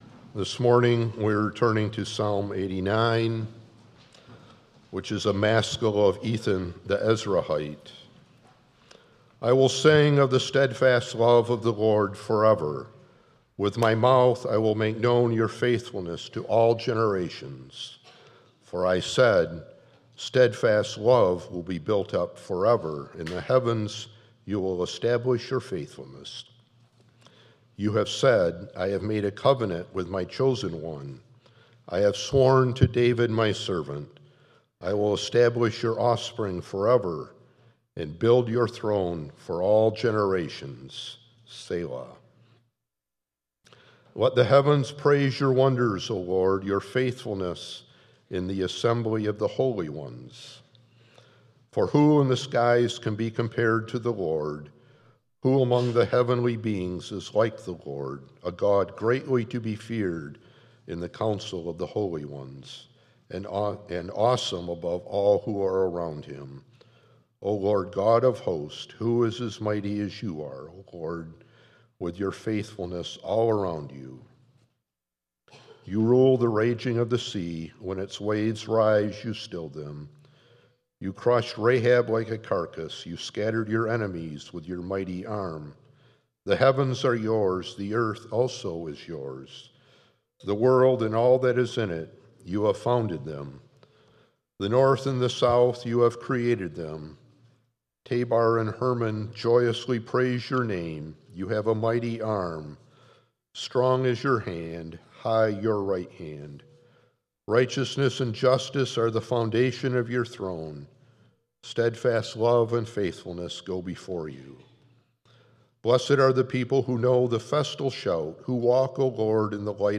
7.27.25 Sermon.m4a